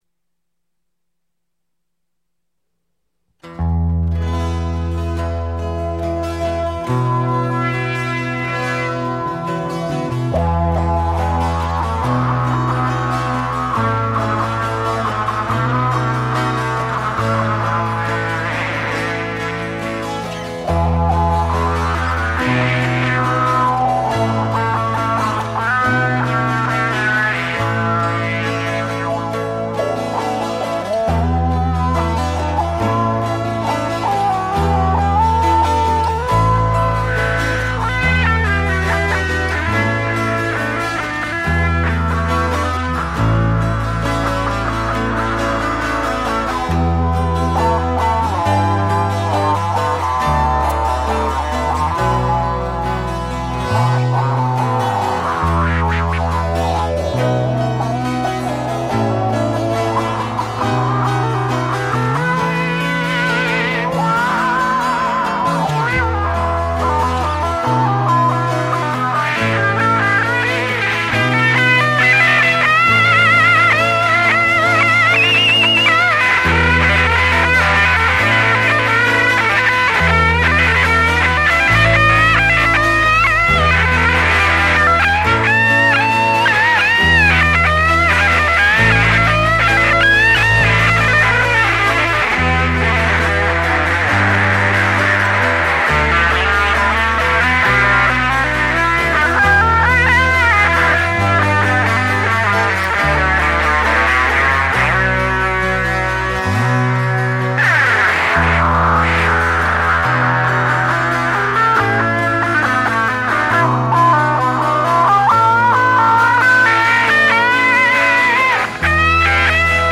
You & Me (instrumental